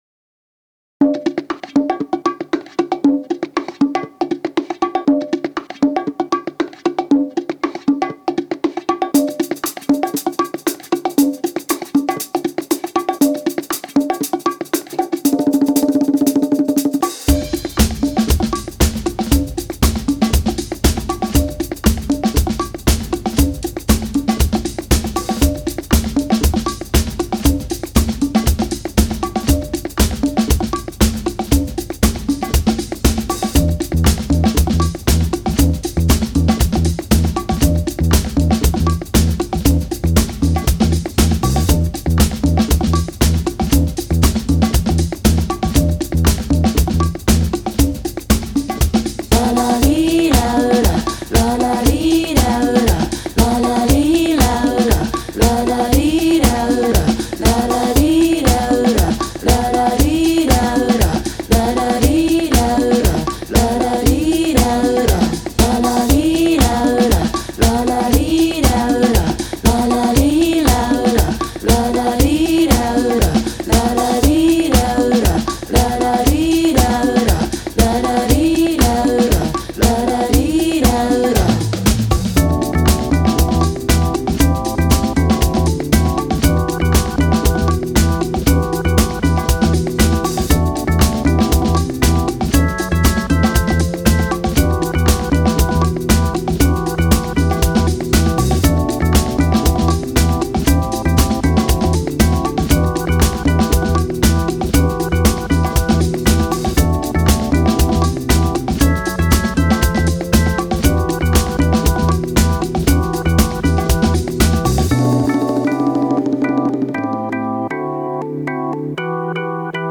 ジャンル(スタイル) JAPANESE POP